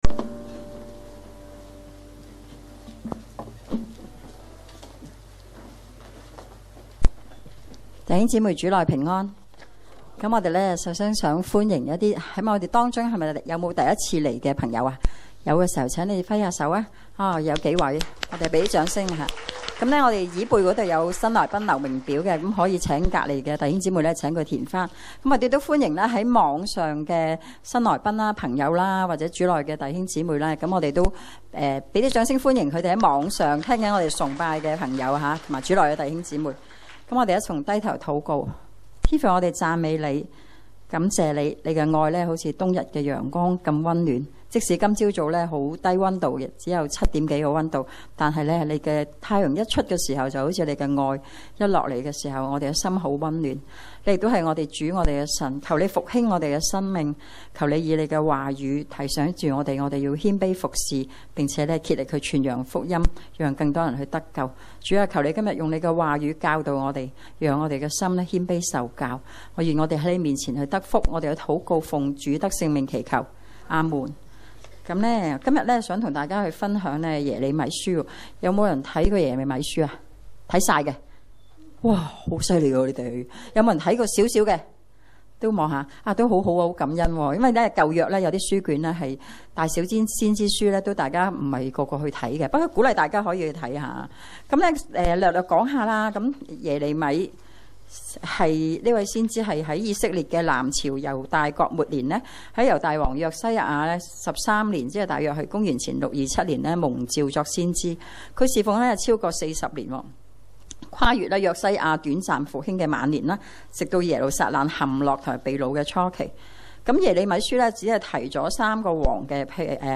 證道集
恩福元朗堂崇拜-早、中堂